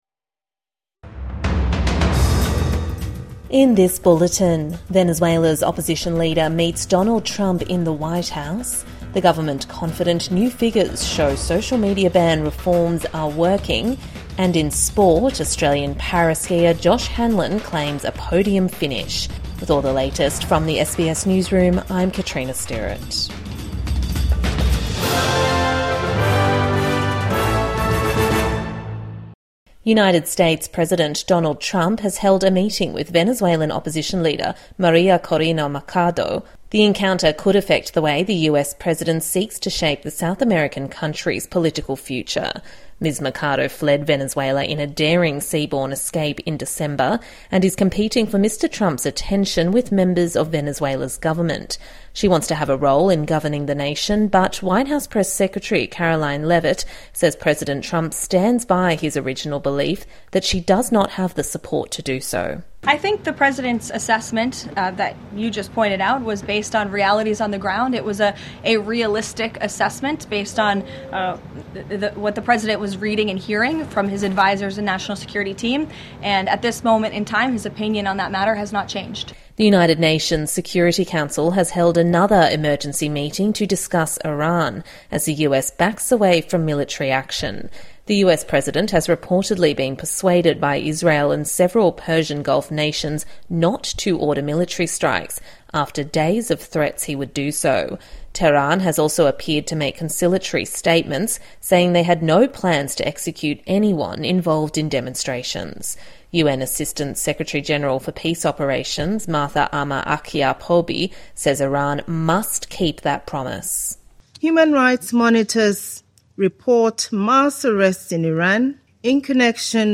Venezuela's opposition leader meets Donald Trump in the White House | Midday News Bulletin 16 January 2026